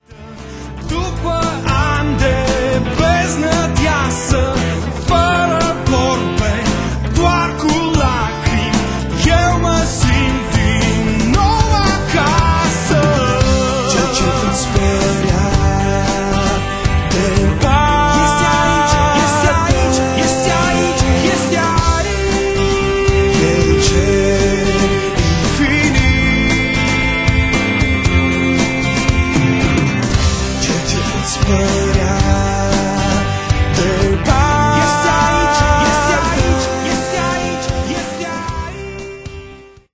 Abordand stilul pop-rock intr-o maniera proprie